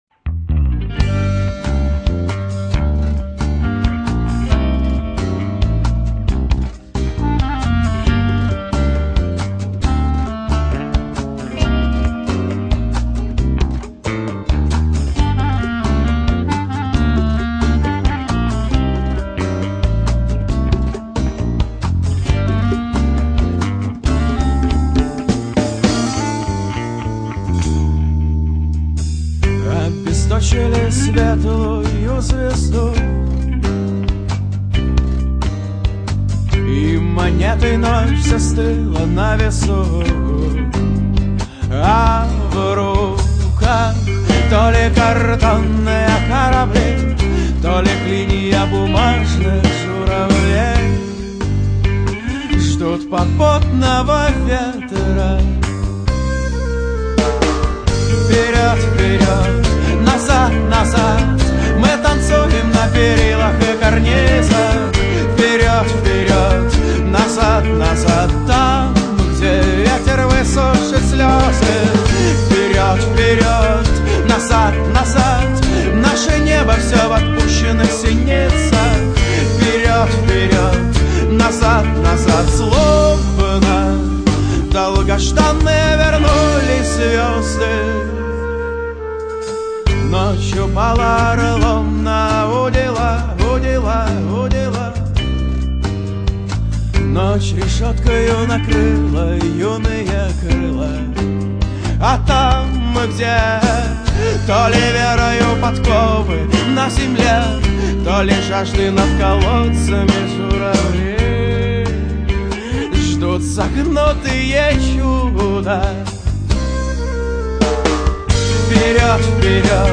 Гитара под гитару